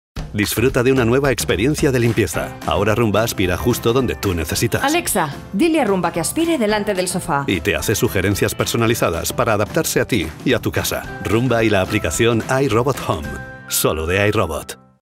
spanish castilian voice talent, spanish freelance voice over. locutor andaluz
kastilisch
Sprechprobe: Werbung (Muttersprache):